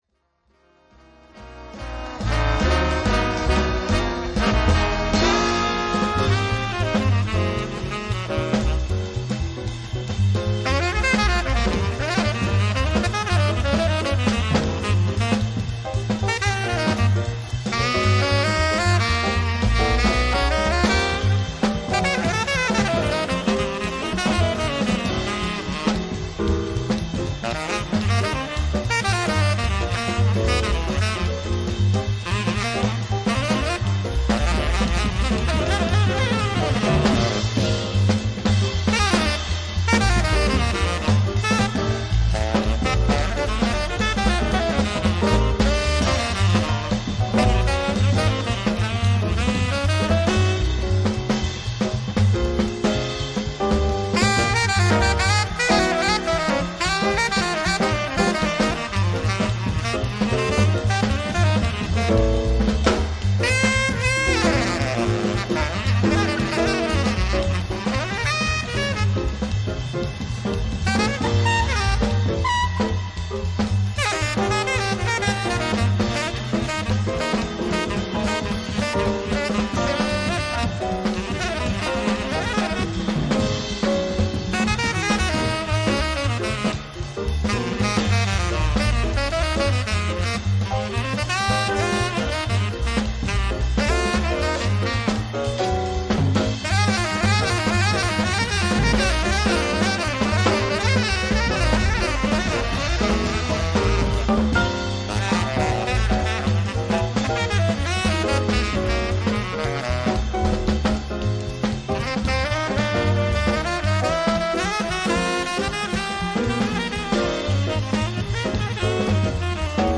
They are so fast and growly!
I do hear a little Sonny in there, certainly some Trane.